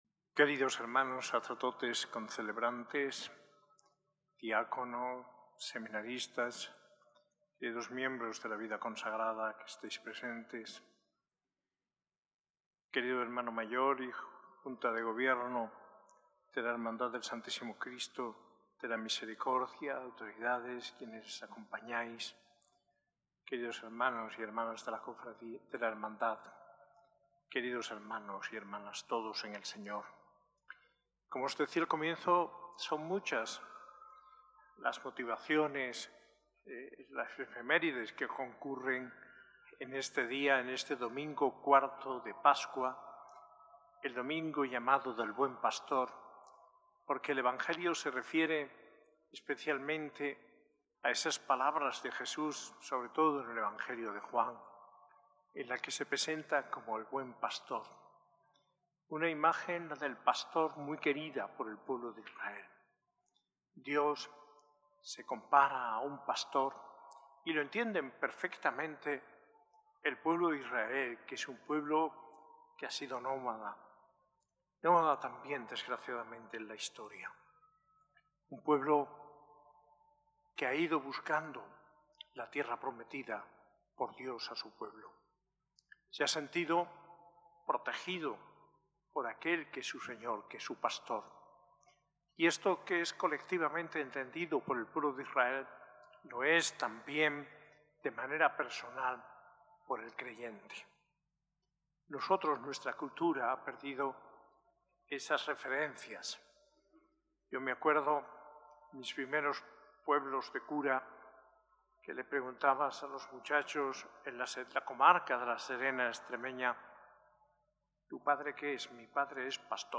Homilía del arzobispo de Granada, Mons. José María Gil Tamayo, en la Eucaristía del IV Domingo del Tiempo Pascual, el 11 de mayo de 2025, en la S.A.I Catedral.